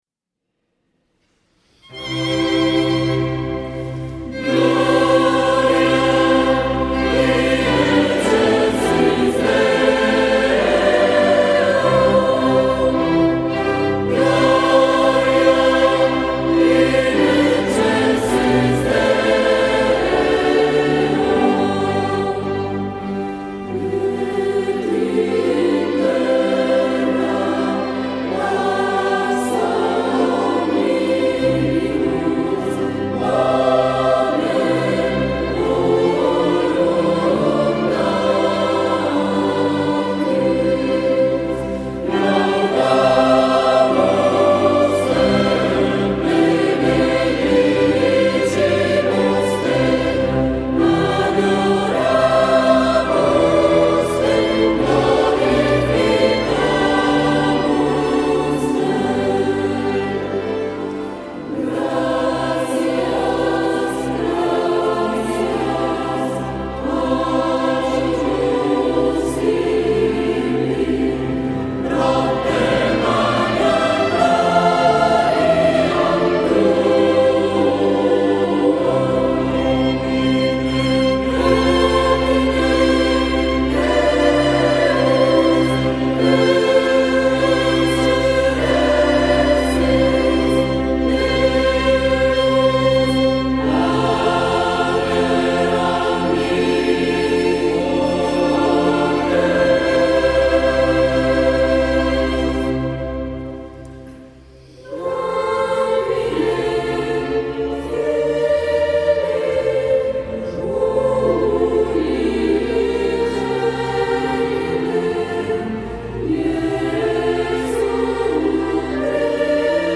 Sabato 05 maggio 2012 la corale ha eseguito la prima edizione del Concerto di S. Eurosia, in collaborazione con l'orchestra "L'Incanto Armonico" di Pisogne (BS).